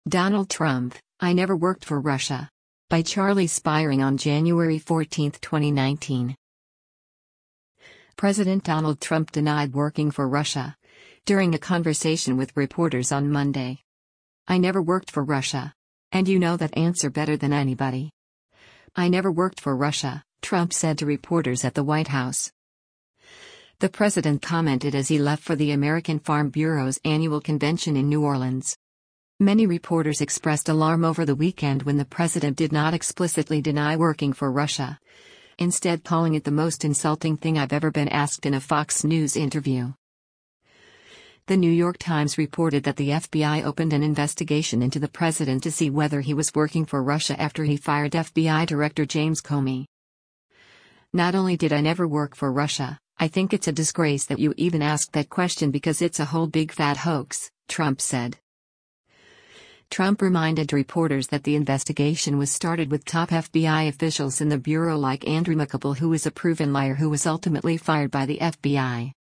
President Donald Trump denied working for Russia, during a conversation with reporters on Monday.
“I never worked for Russia. And you know that answer better than anybody. I never worked for Russia,” Trump said to reporters at the White House.